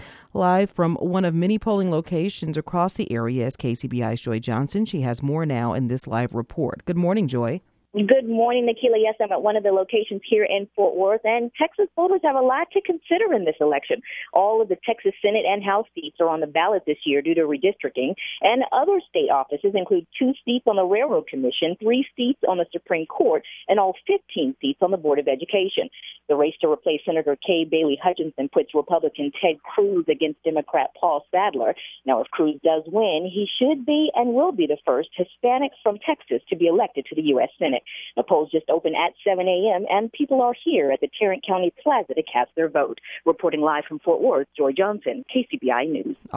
Texas voters broke records for early voting, and state officials prepare for the crowds for those that didn't take advantage of that time! Here's coverage from one of the polling locations in the Tarrant County! Reporting Live 7:00 am.